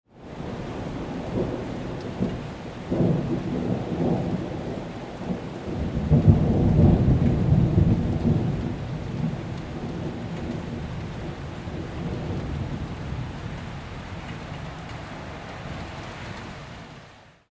Thunder And Rainstorm Ambience Sound Effect
A summer storm hits the city with heavy rain. Distant thunder rumbles in the background. Strong rainfall creates an intense urban storm atmosphere.
Thunder-and-rainstorm-ambience-sound-effect.mp3